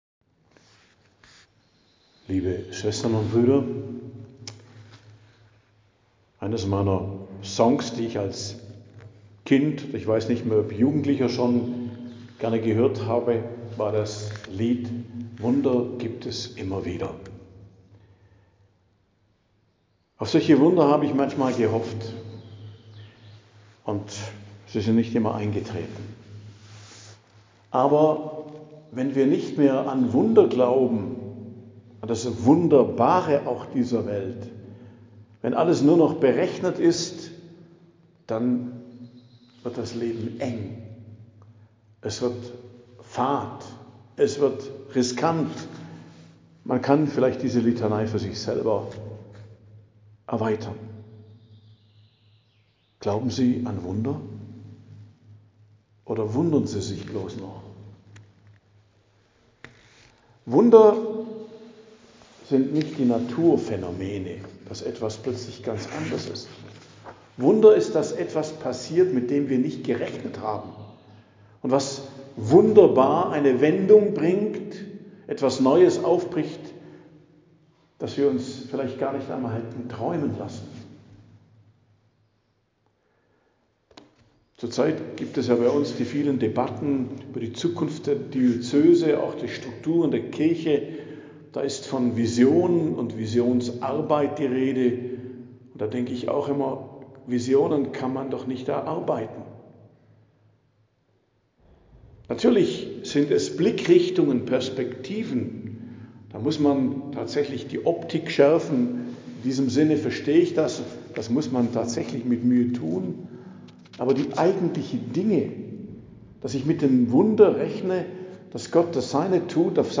Predigt am Donnerstag der 14. Woche i.J., 10.07.2025 ~ Geistliches Zentrum Kloster Heiligkreuztal Podcast